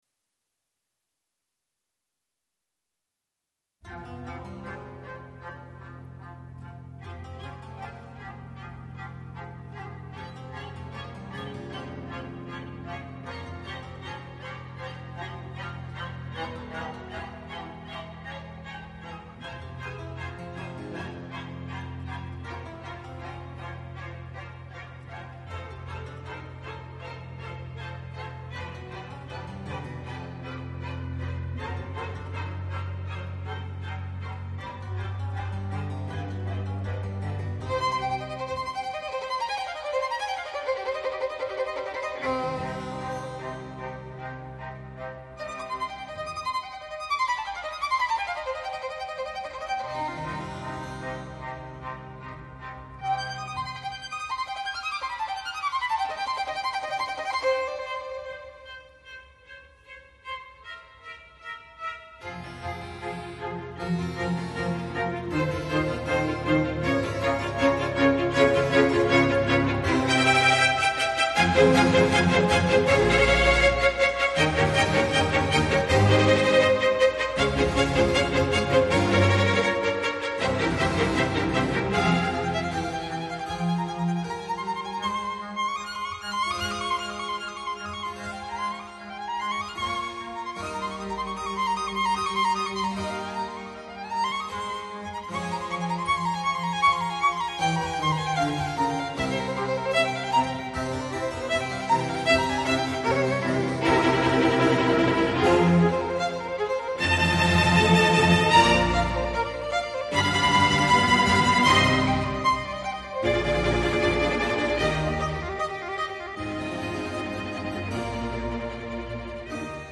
Жанр: Classical